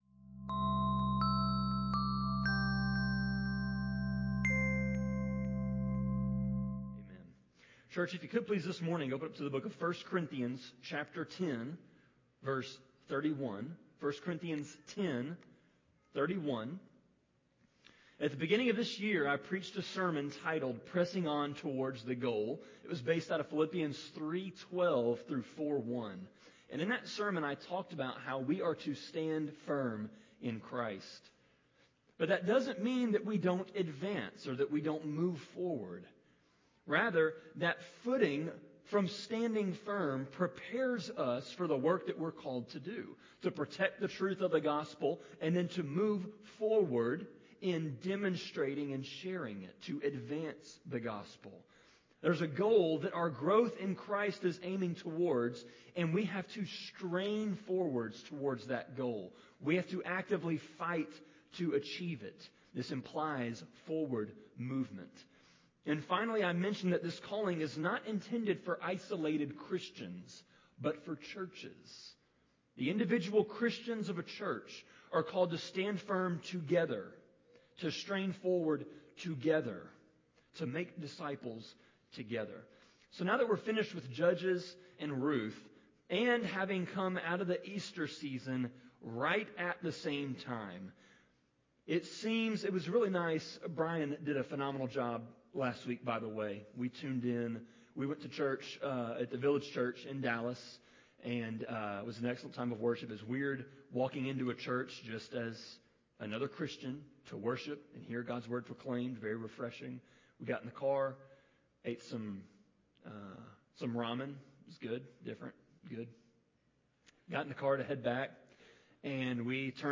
Sermon-26.4.19-CD.mp3